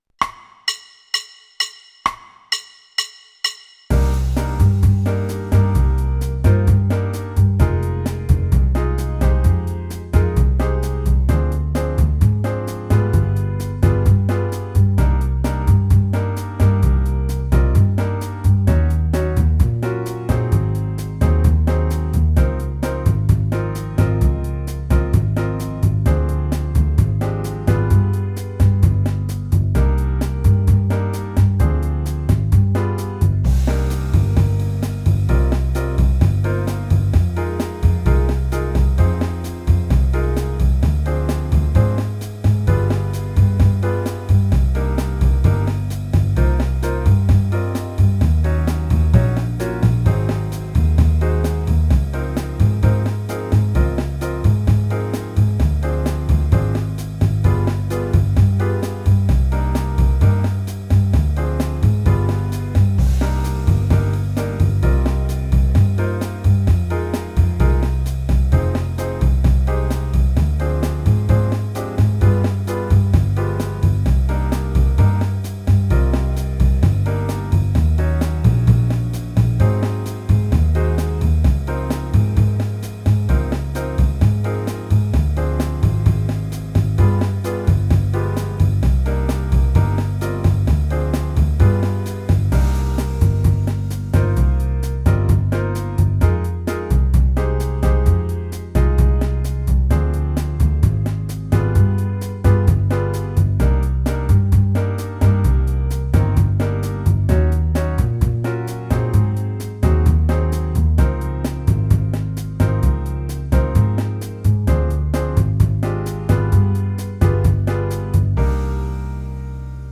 Latin
(in c minor)
backing track: 4x, qn=130))